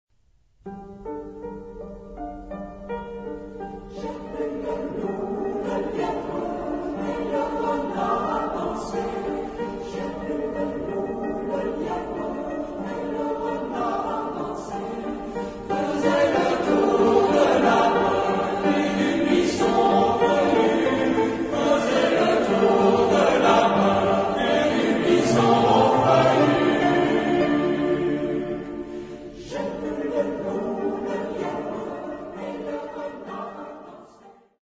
Genre-Style-Form: Secular ; Popular ; Bourrée
Mood of the piece: dancing
Type of Choir: ST  (2 mixed voices )
Tonality: G minor
Origin: Auvergne (France)